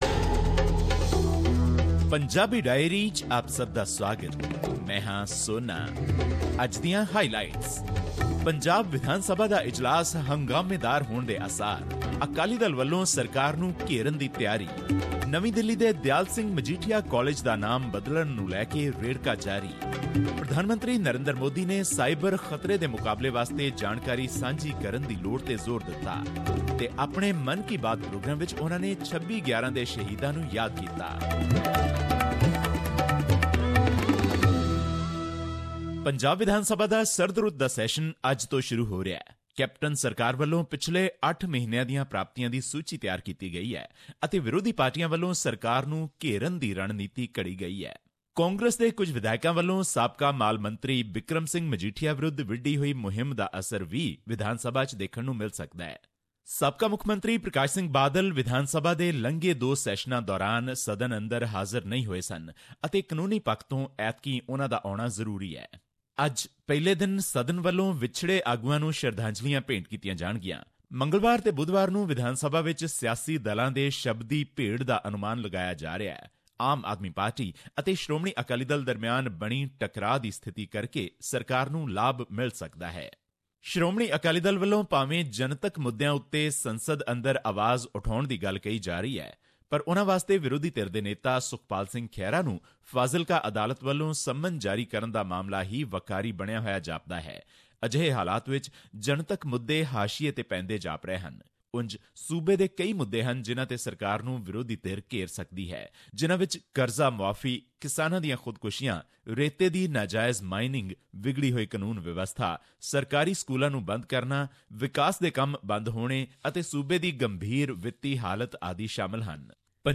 The weekly Punjabi Diary report was presented on SBS Punjabi program on Nov 27, 2017.
Here is the podcast in case you missed hearing it on the radio.